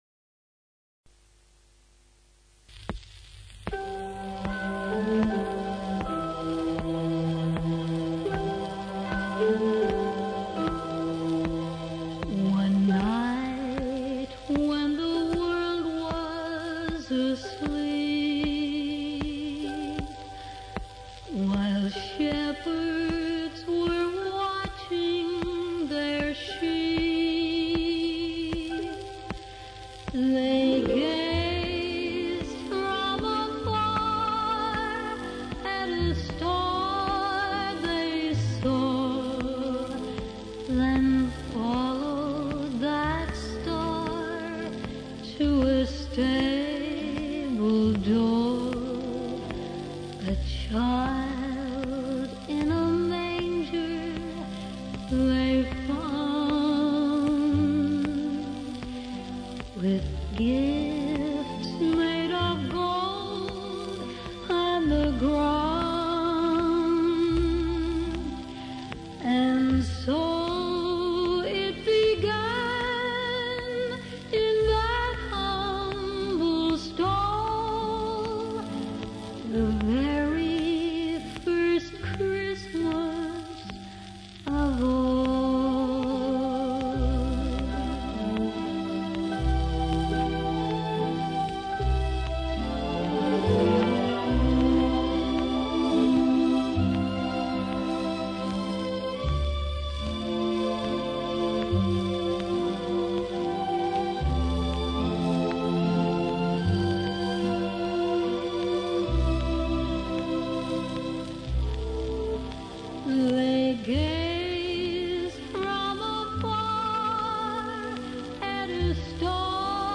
from an old 78 rpm shellac record